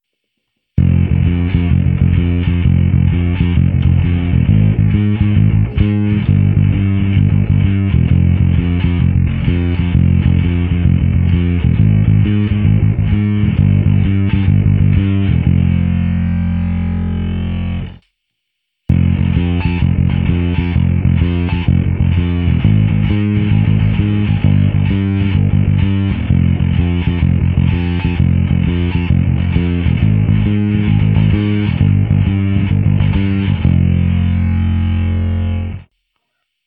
A to samé ještě s přidáním zkreslení, protože to téhle divošce rozhodně sluší.